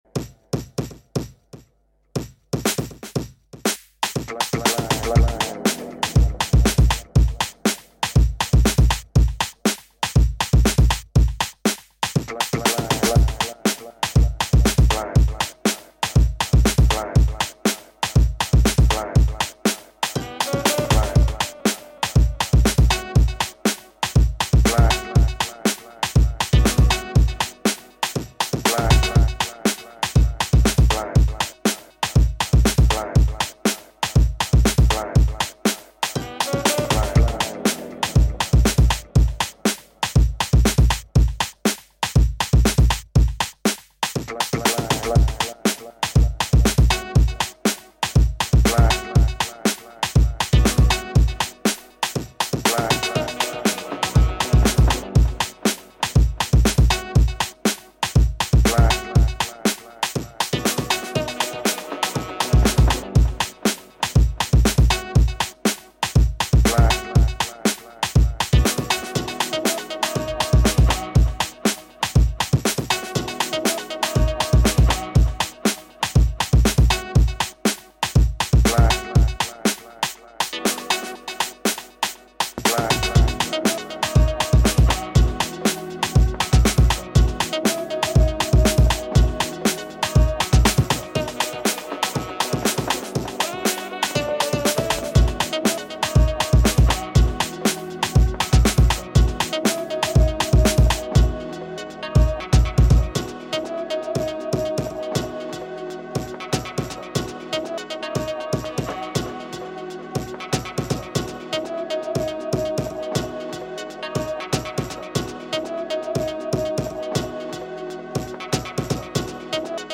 I wasn’t strict with my usage here - took what I wanted and layered in some of my own drums - treated it more like I would normal sample content and had fun.
Instrumental MP3
Really enjoyed your delays on this one! It feels like every sound is dancing with each other, and that vocal exit on the video is delicious.